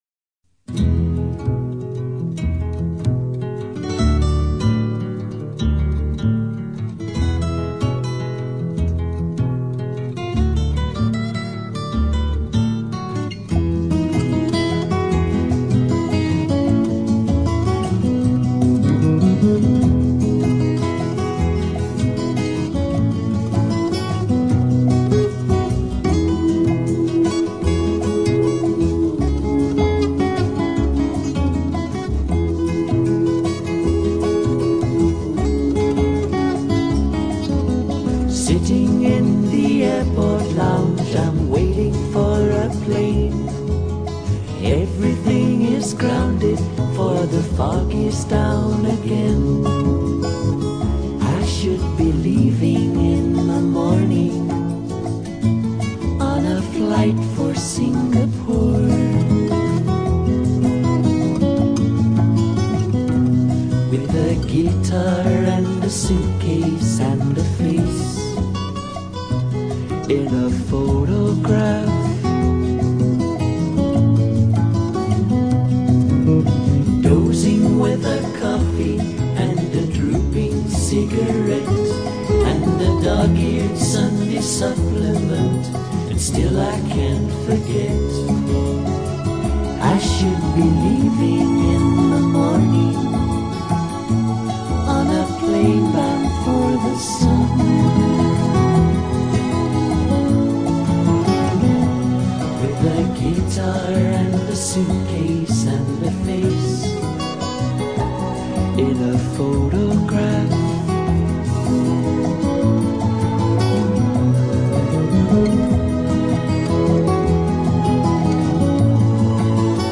banda británica de folk rock y rock progresivo
Son delicados, inspirados, refinados, exquisitos.